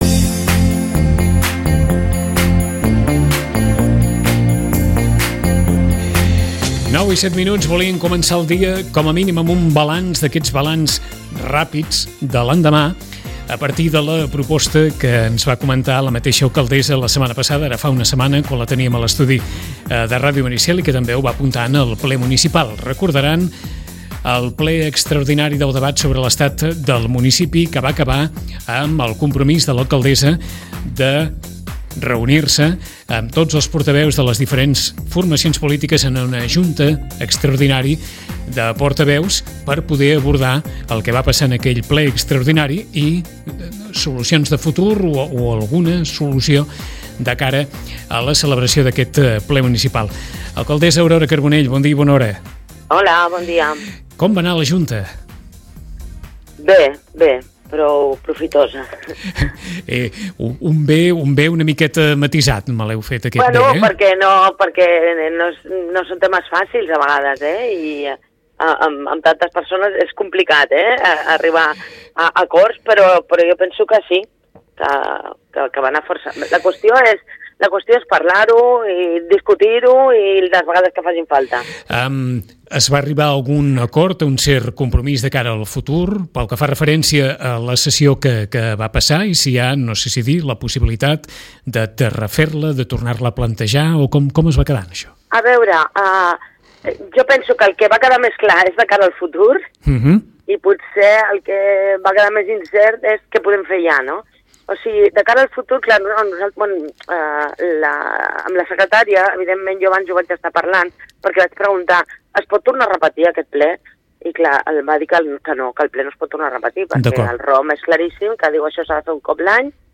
L’alcaldessa Aurora Carbonell ha fet balanç de la reunió.